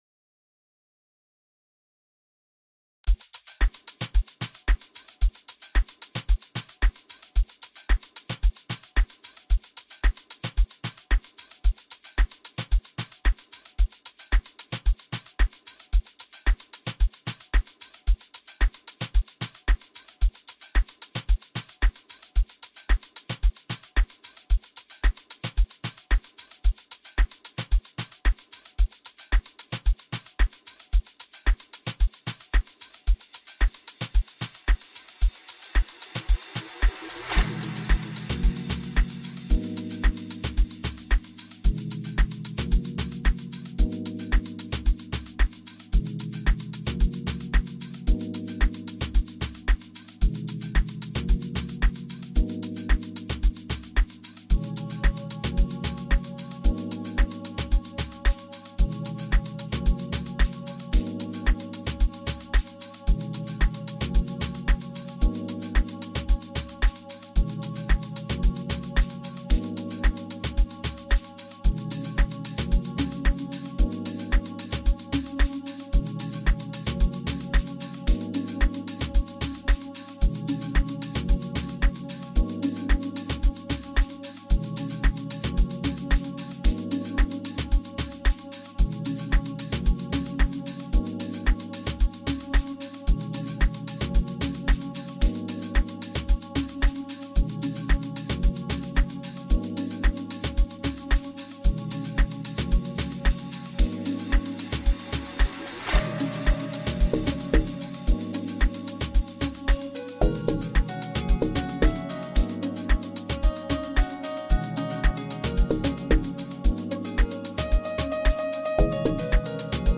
Lounge Music